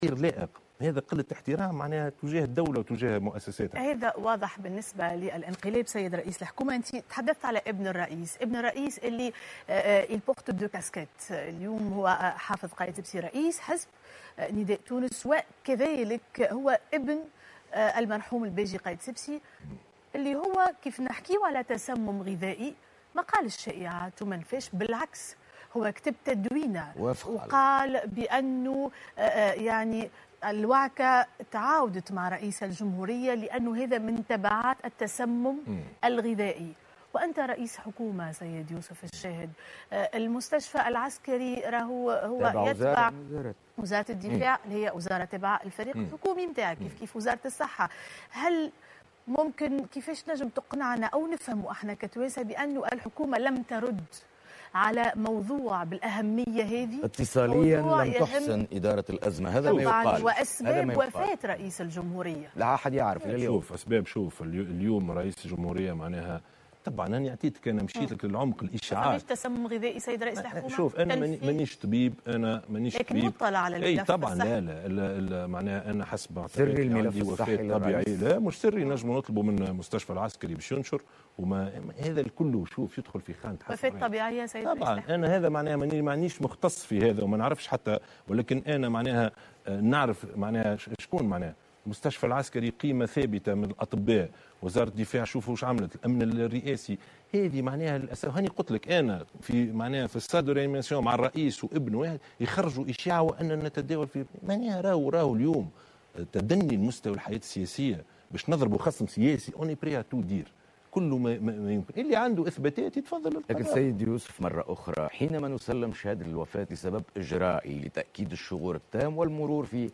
قال رئيس الحكومة يوسف الشاهد في حوار على القناة الوطنية الأولى مساء اليوم الخميس إن الشائعات التي أحاطت بالحالة الصحية للرئيس الباجي قايد السبسي والأيام الأخيرة له كانت كثيرة و مزعجة.